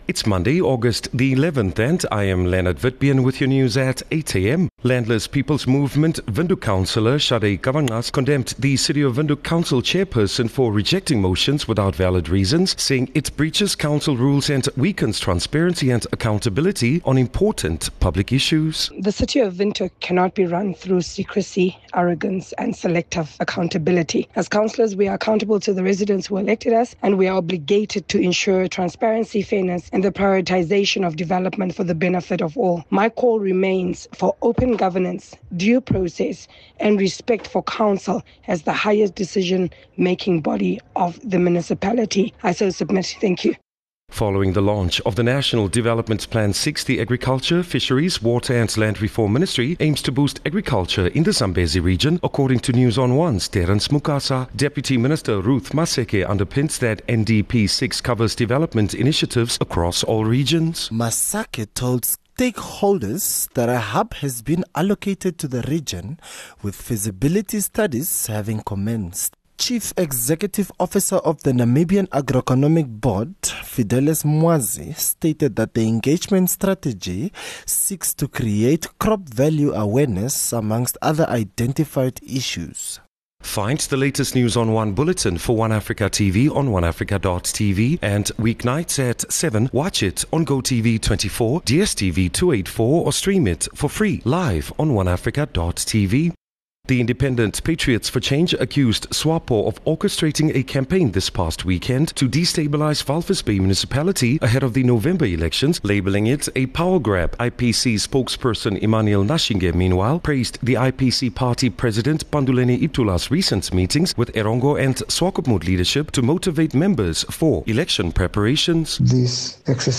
11 Aug 11 August-8am news